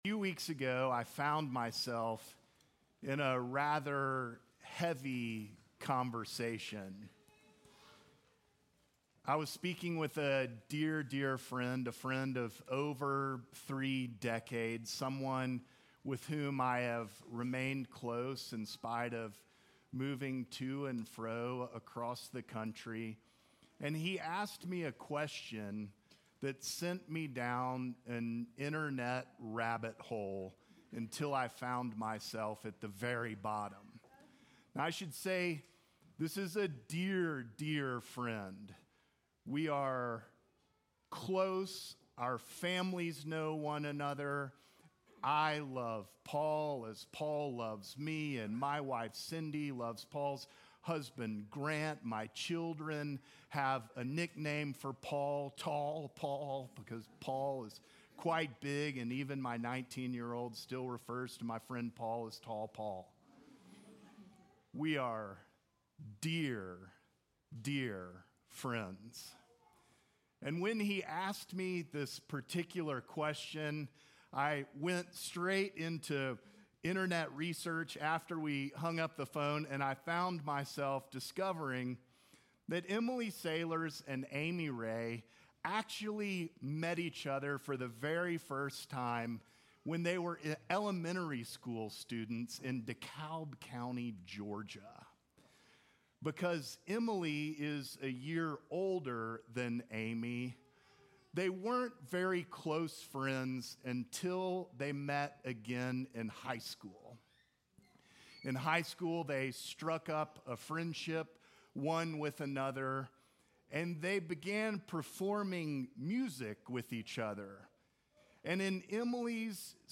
Sermons from St. John's Episcopal Church Easter Sunday